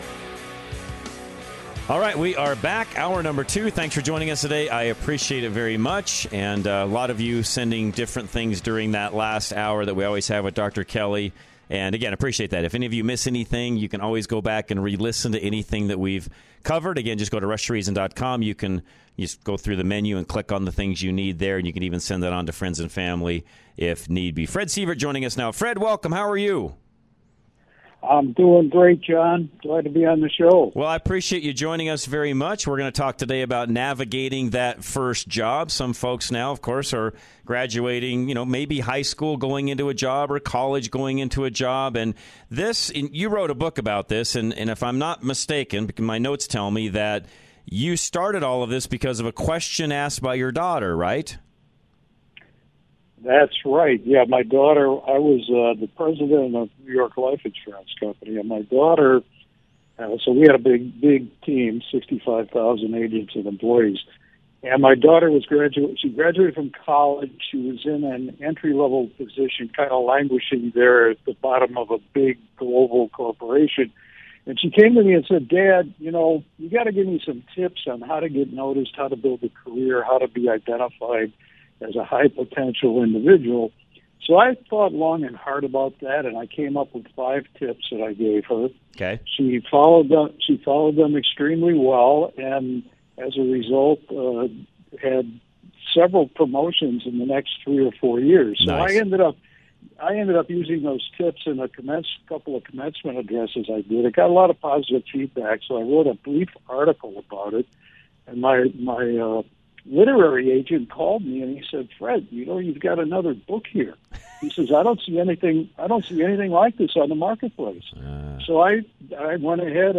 Rush To Reason - Interviews